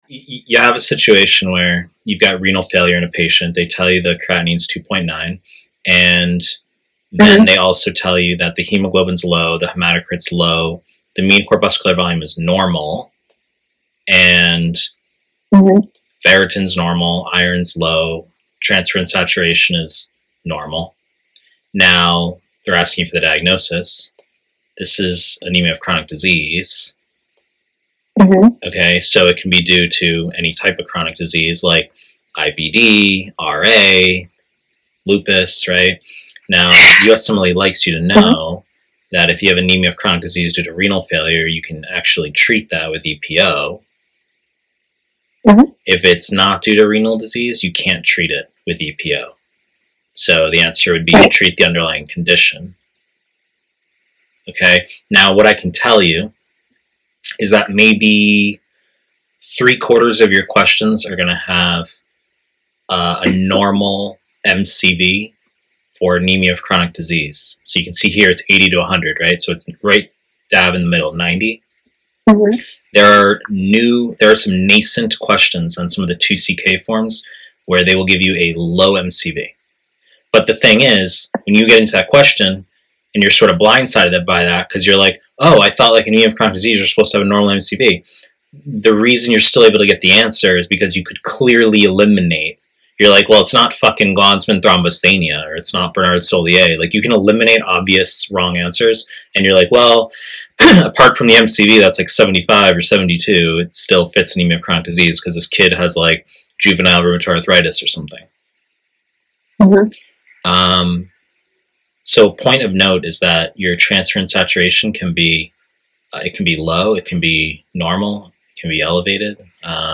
Internal Medicine / Pre-recorded lectures